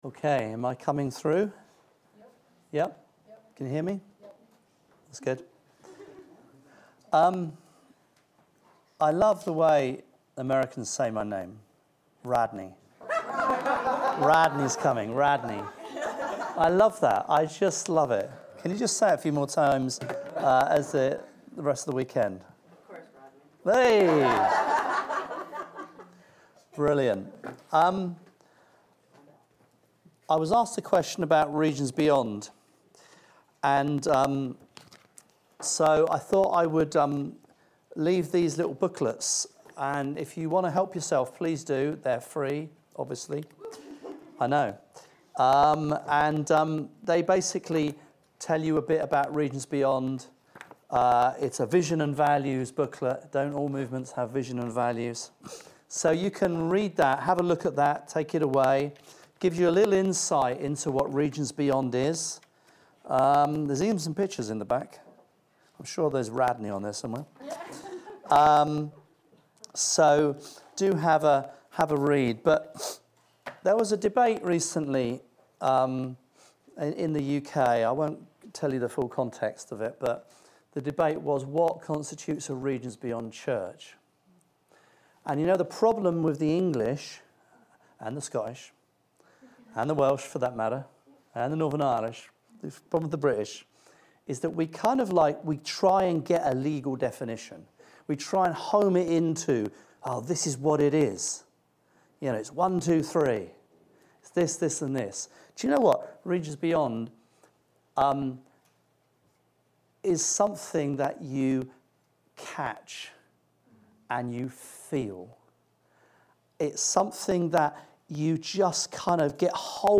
Sermons | Victory Hill Church
Weekend Away 2024